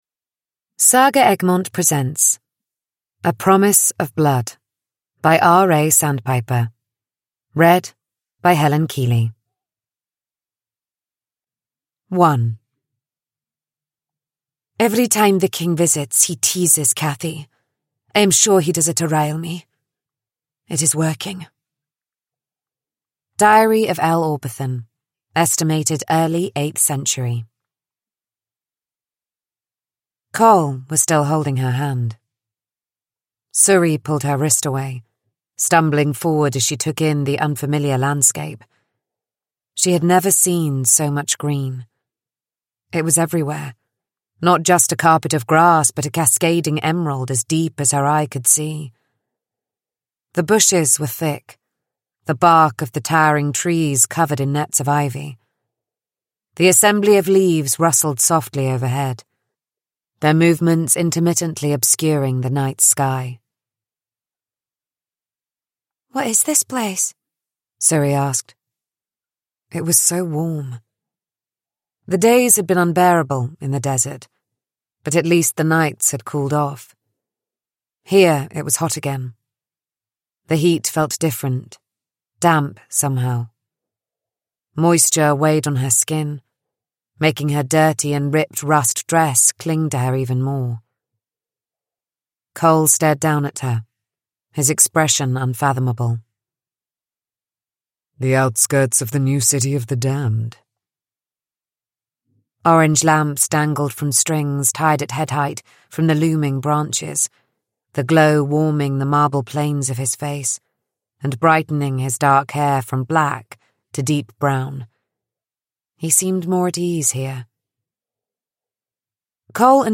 A Promise of Blood: A darkly romantic, high-stakes fantasy adventure – Ljudbok